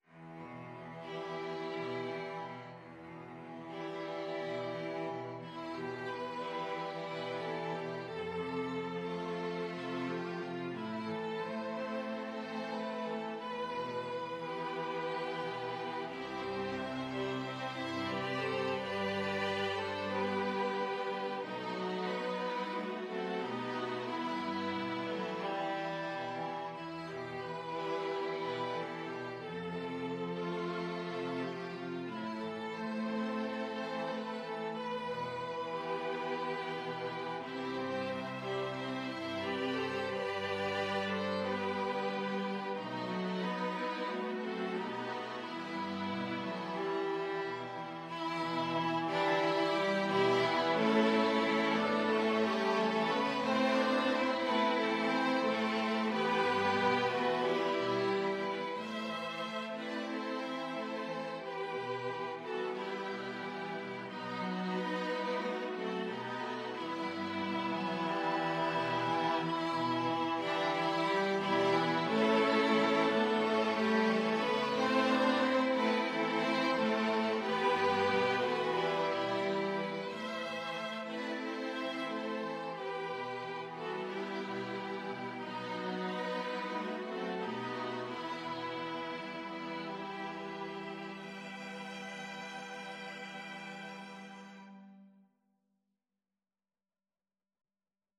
Violin 1Violin 2Violin 3Cello 1Cello 2
4/4 (View more 4/4 Music)
Gently Flowing =c.90
String Ensemble  (View more Easy String Ensemble Music)
Classical (View more Classical String Ensemble Music)